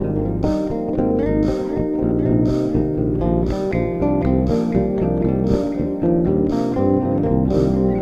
Loop (00:08)